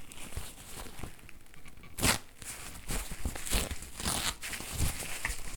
Rasgando y arrugando tela
Grabación sonora del sonido producido por el rasgado y arrugado de una tela
Sonidos: Acciones humanas